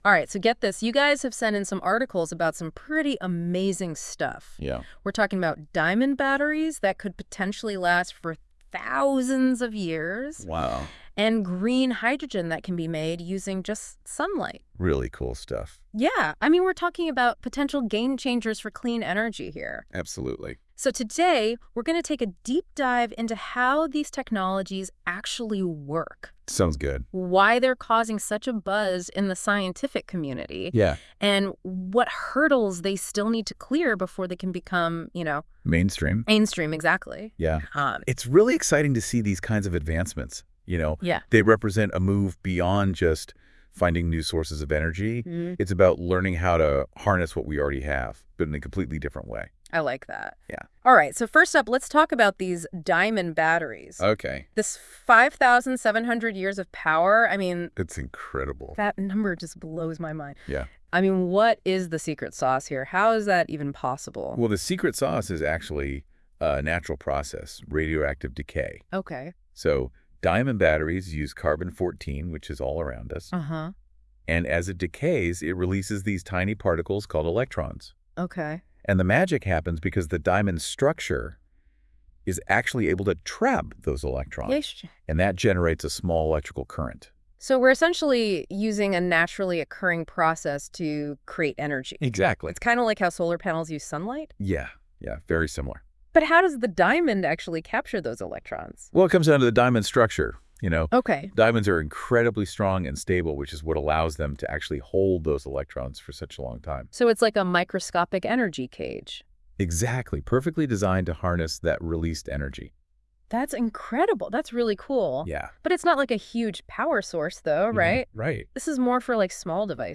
Listen to a convo on it….